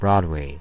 Ääntäminen
Ääntäminen US Tuntematon aksentti: IPA : /ˈbɹɔdweɪ/ Haettu sana löytyi näillä lähdekielillä: englanti Käännöksiä ei löytynyt valitulle kohdekielelle.